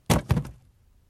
Звук удара ногой о мебель